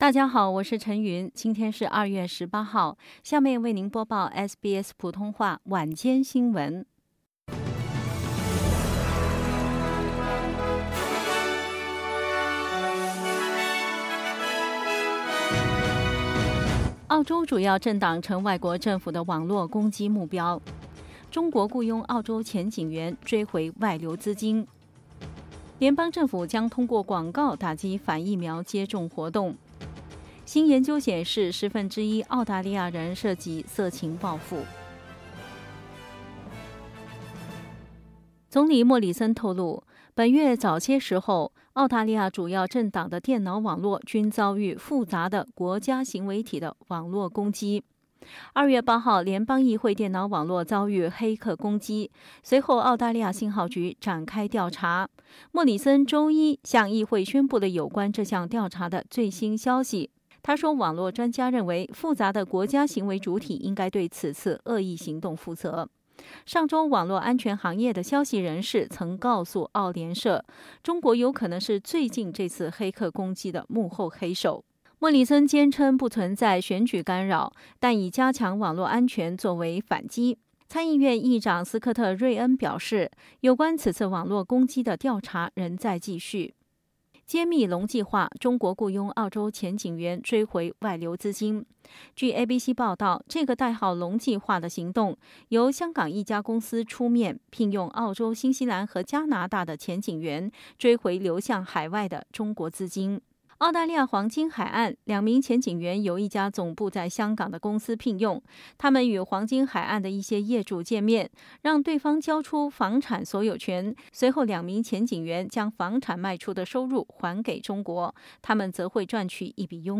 SBS晚新闻（2月18日）